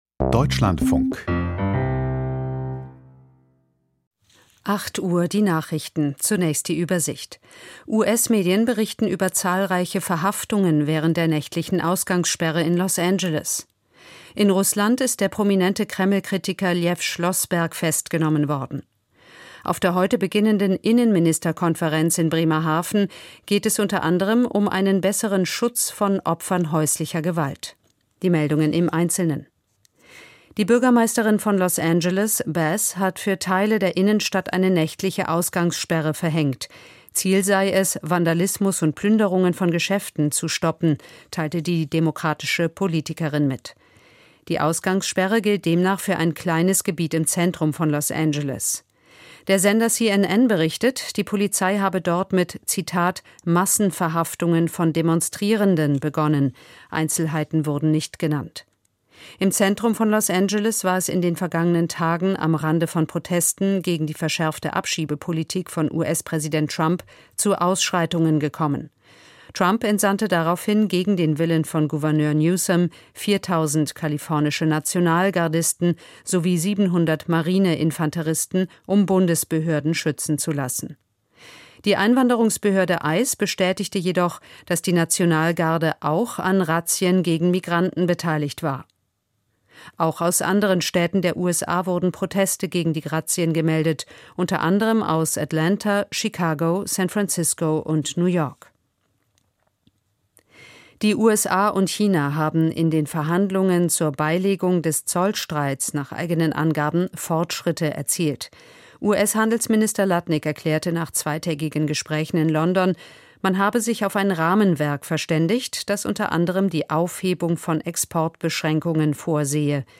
Steinmeier bei Netanjahu: Interview